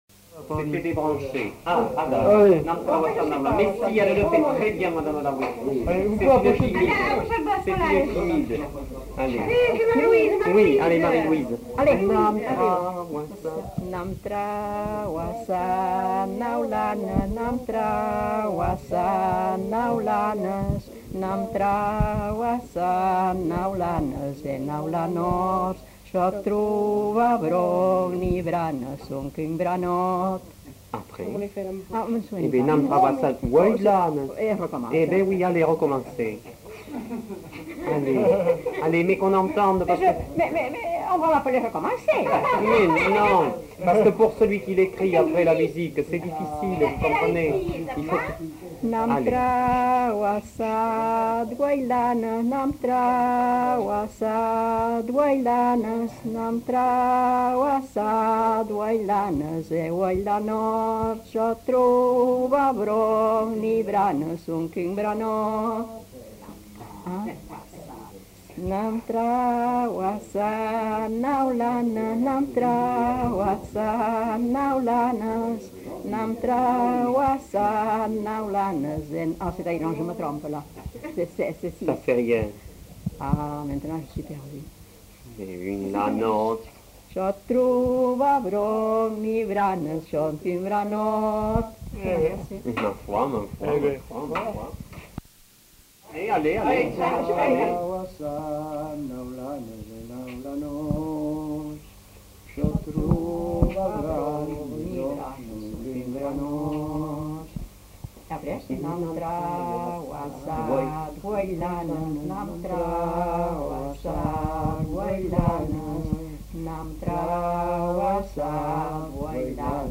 Lieu : Luxey
Genre : chant
Effectif : 2
Type de voix : voix de femme
Production du son : chanté
Classification : chansons de neuf
Notes consultables : En fin de séquence, le chant est repris par un homme et une femme non identifiés.